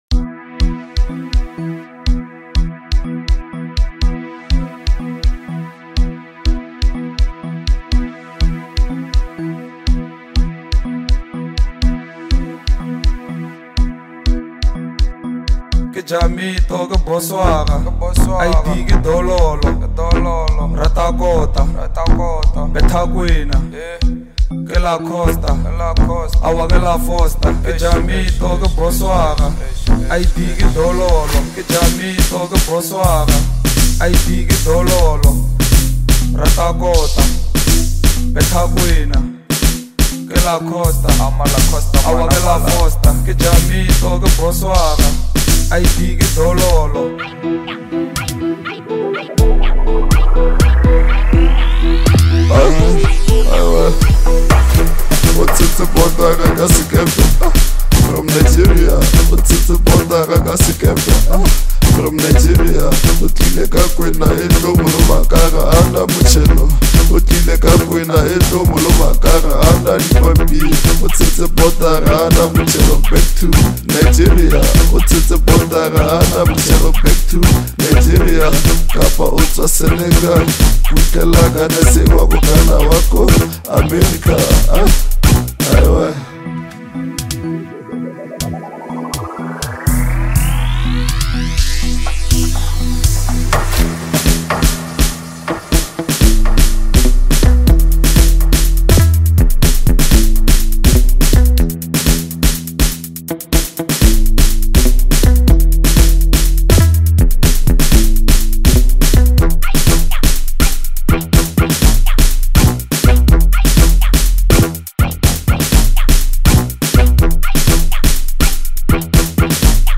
Home » Amapiano » Lekompo